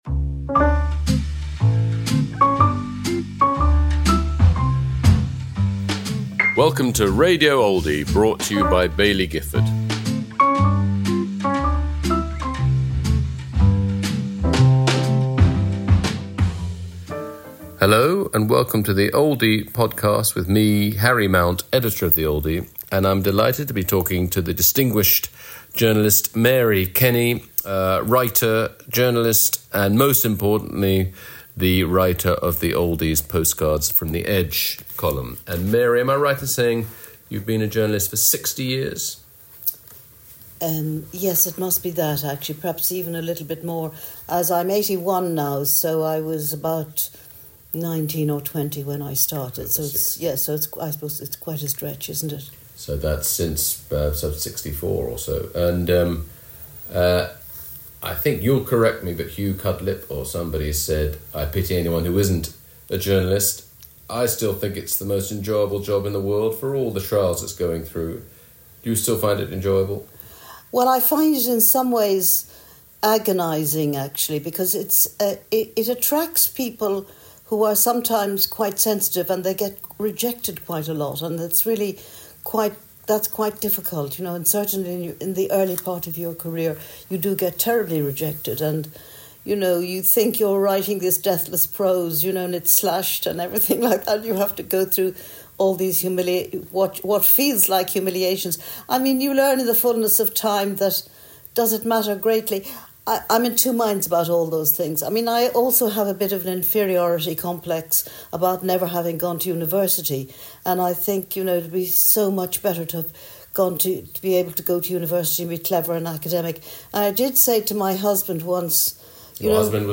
Mary Kenny in conversation with Harry Mount